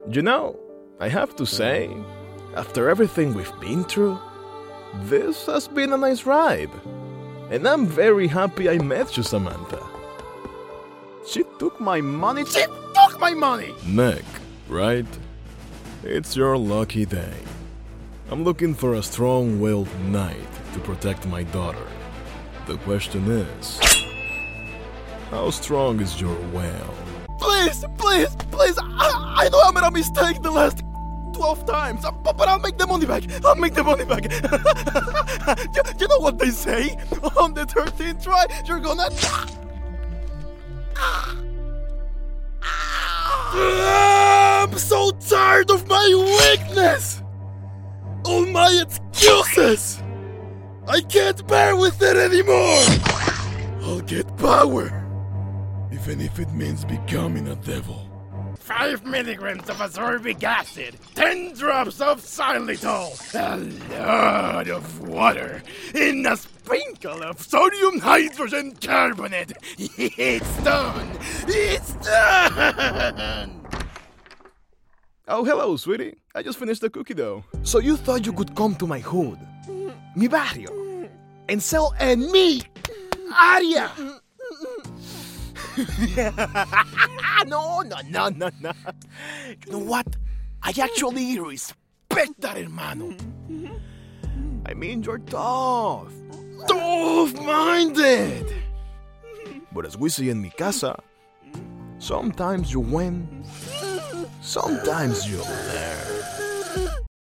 English Videogame Demo
Videogame Sample
•   Broadcast Quality Audio
Microphone: Sennheiser MKH 416 / Rode SS